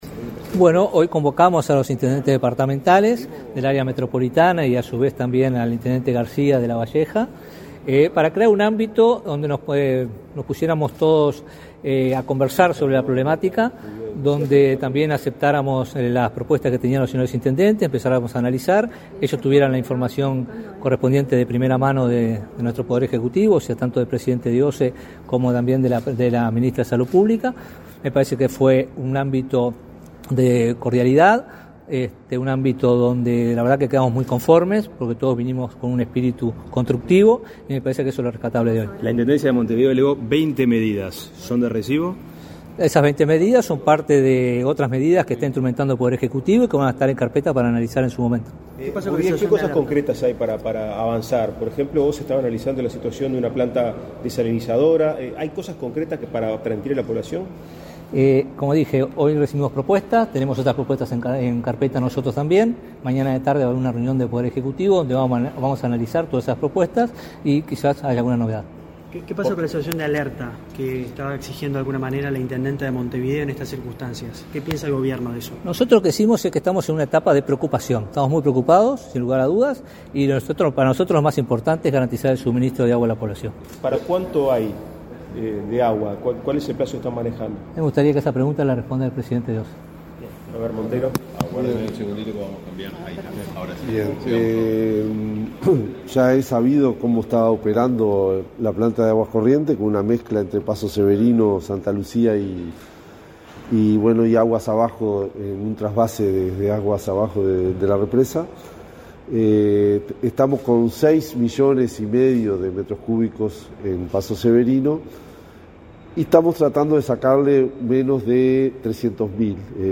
Declaraciones a la prensa de Bouvier, Rando y Montero